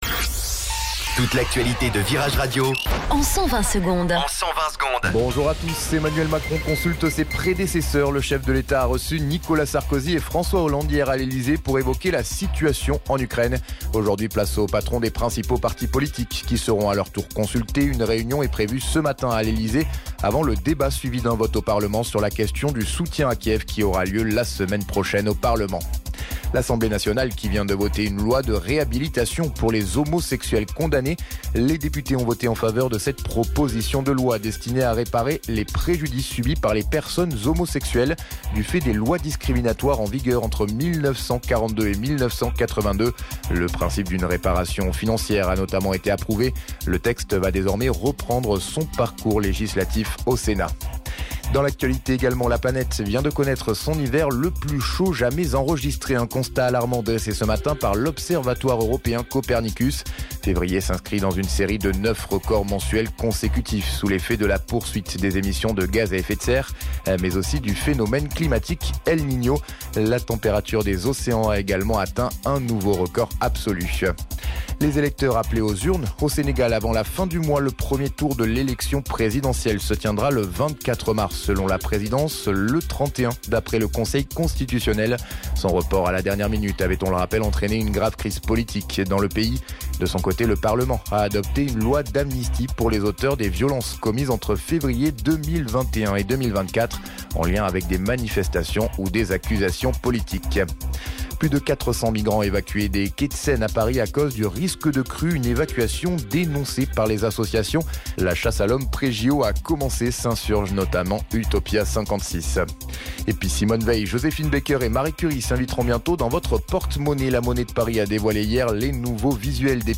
Flash Info Lyon 07 Mars 2024 Du 07/03/2024 à 07h10 Flash Info Télécharger le podcast Partager : À découvrir Oasis à la rescousse de New Order ?